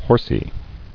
[hors·y]